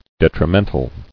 [det·ri·men·tal]